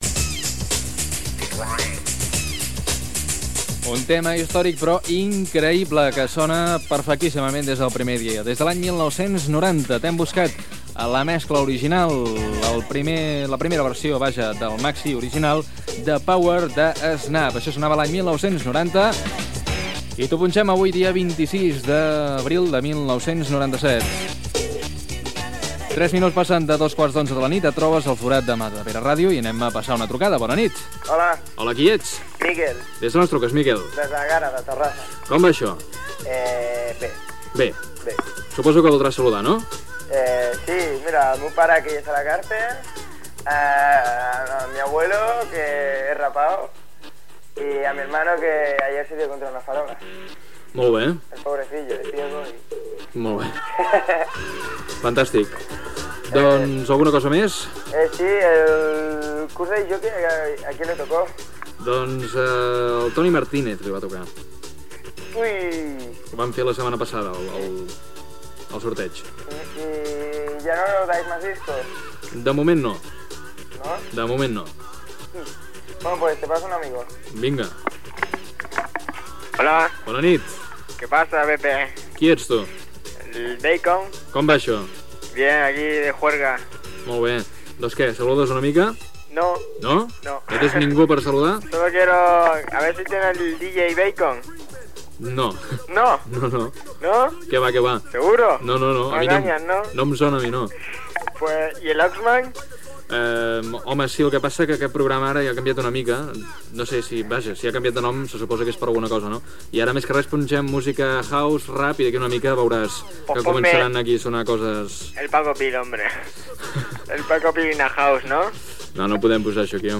Presentació de temes musicals i participació telefònica dels oients.
Musical
FM